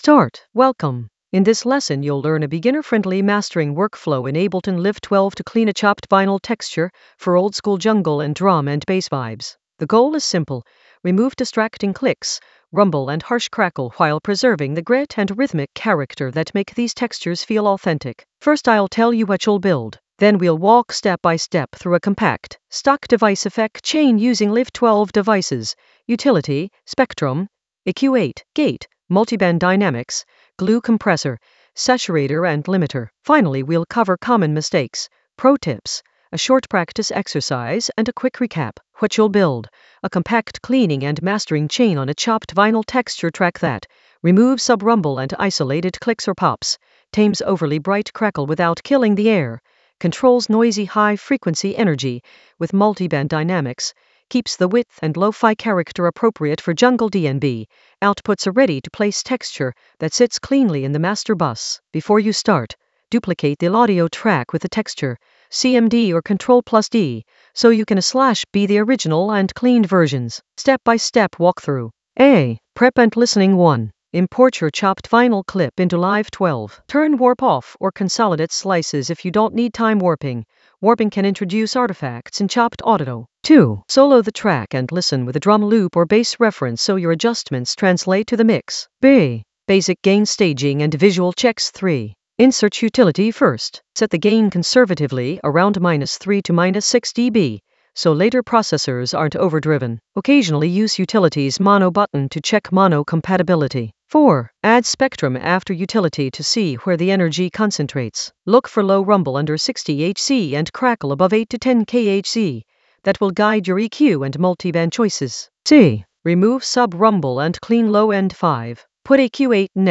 An AI-generated beginner Ableton lesson focused on Clean a chopped-vinyl texture in Ableton Live 12 for jungle oldskool DnB vibes in the Mastering area of drum and bass production.
Narrated lesson audio
The voice track includes the tutorial plus extra teacher commentary.